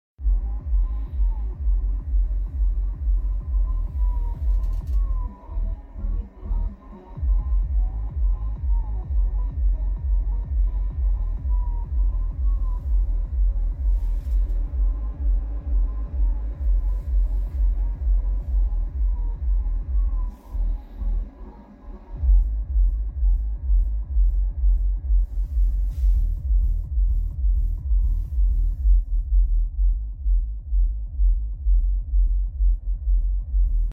18 zoll subwoofer mal wieder sound effects free download
18 zoll subwoofer mal wieder am laufen